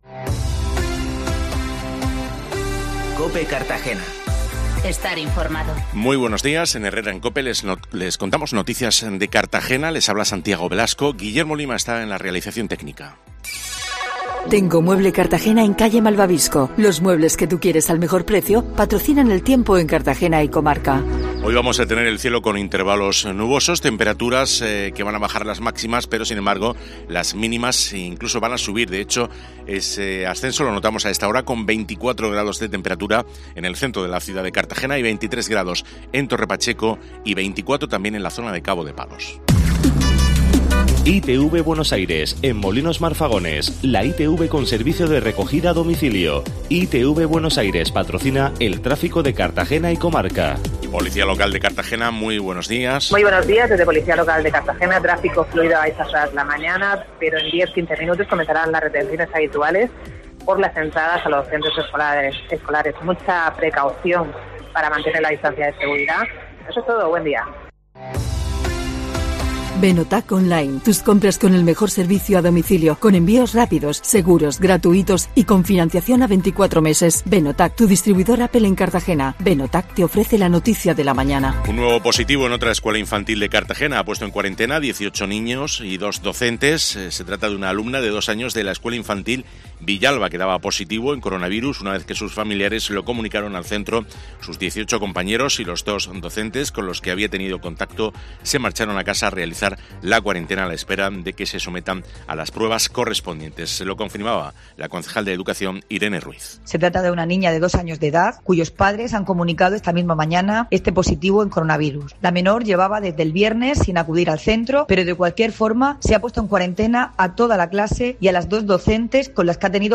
Informativo matinal en Cartagena